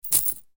Coin Sound